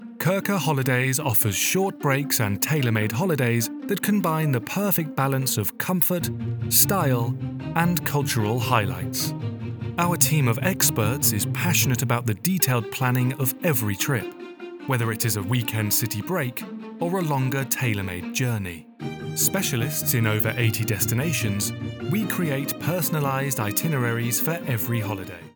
An engaging and warm British voice.
Kriker Holidays Online Ad
Middle Aged